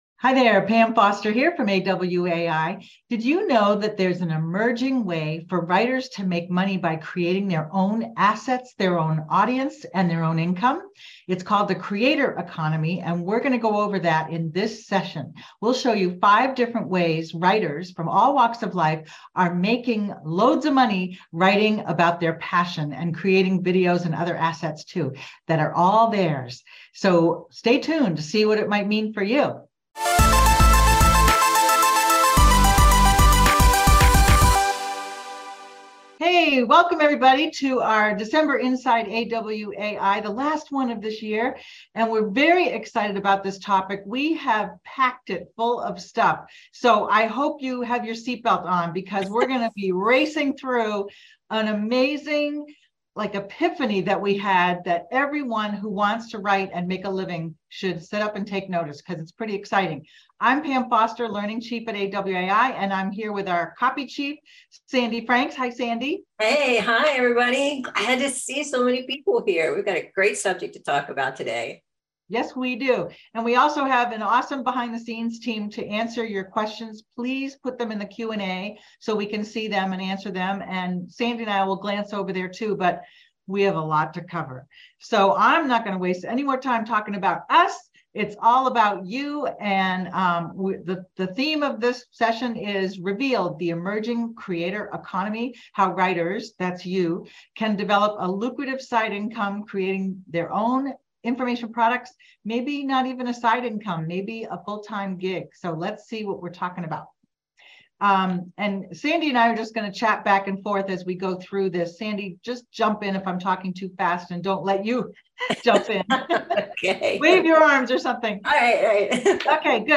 Inside AWAI Webinar and Q&A: Revealed: The Emerging "Creator Economy" How Writers Can Develop a Lucrative Side Income Creating Their Own Information Products